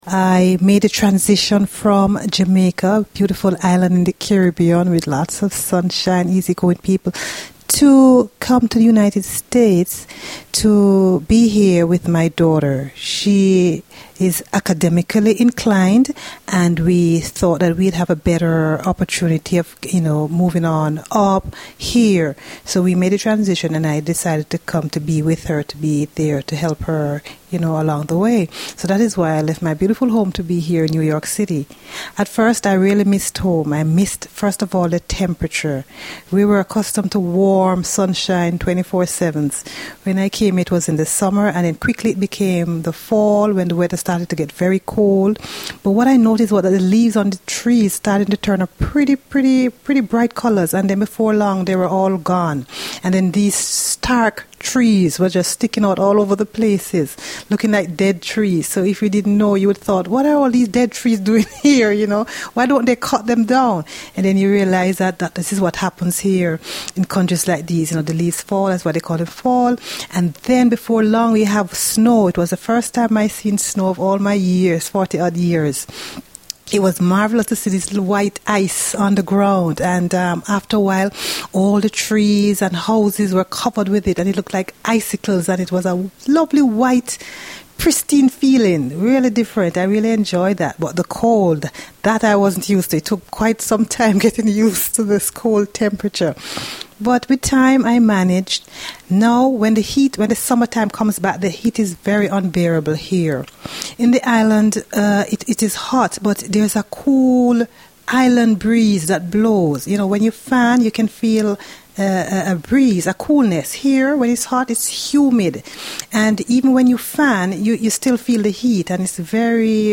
Two Jamaicans in New York